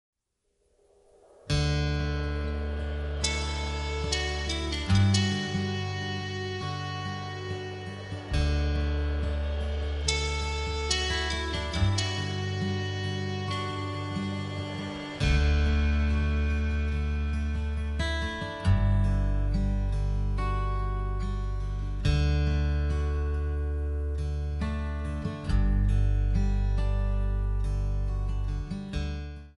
D
MPEG 1 Layer 3 (Stereo)
Backing track Karaoke
Pop, Duets, 1990s